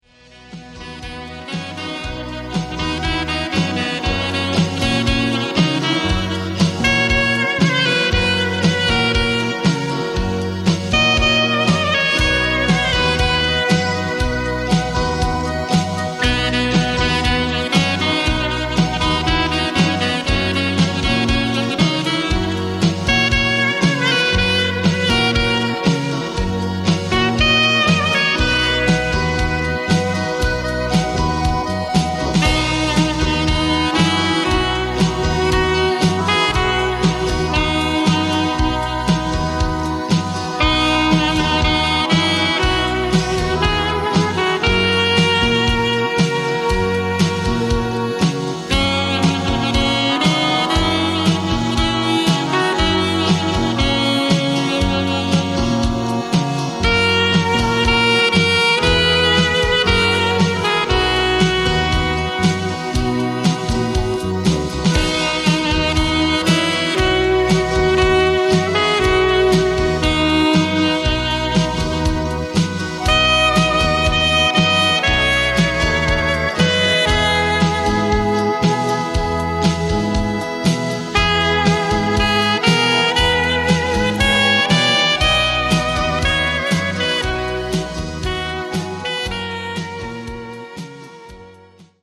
Modern